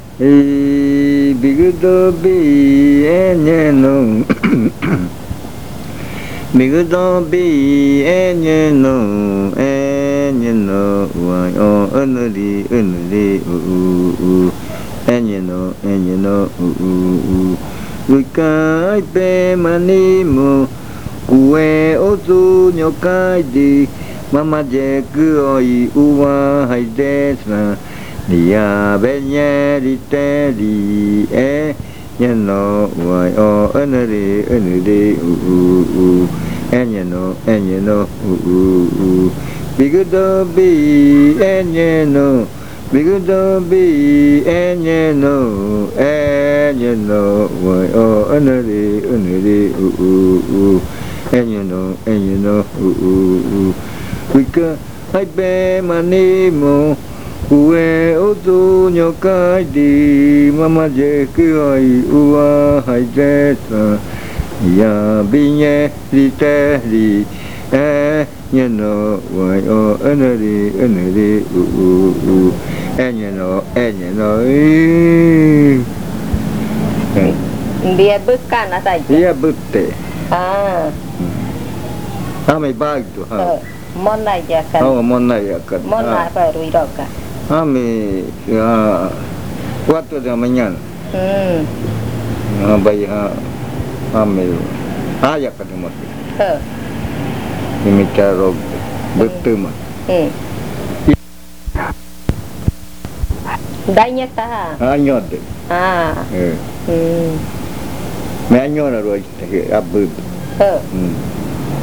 Leticia, Amazonas
Se baila con pasos fuertes doblando la rodilla.
It is danced with strong steps bending the knee.
This chant is part of the collection of chants from the Yuakɨ Murui-Muina